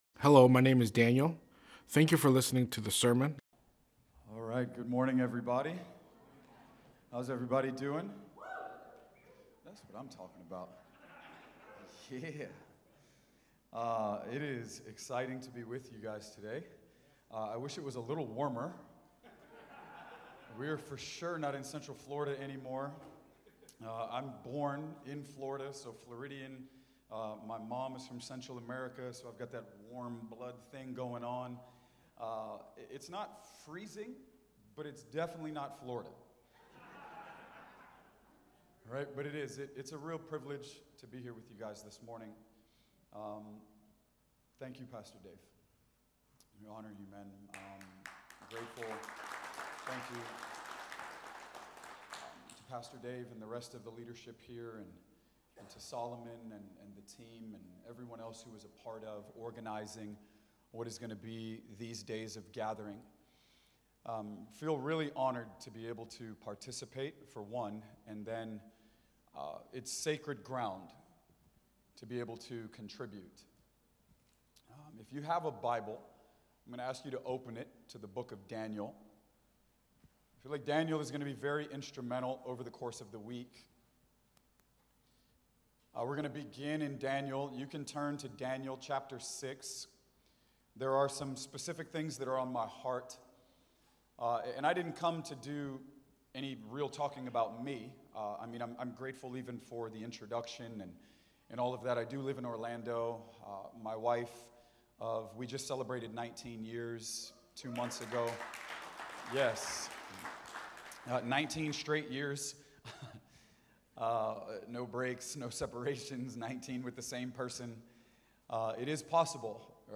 Sunday Morning Bible Study